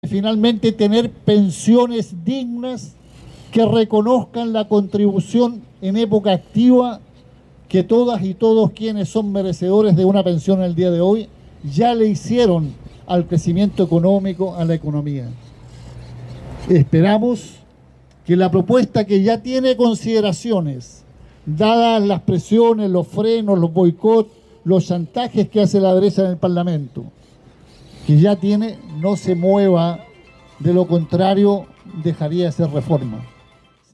Junto a miembro de la Comisión Política del Partido Comunista y acompañado de una delegación de dirigencias comunales, regionales y militantes, el presidente de la colectividad, Lautaro Carmona, dio cuenta del aporte histórico y político de Luis Emilio Recabarren, fundador del partido de izquierda, a cien años de su fallecimiento.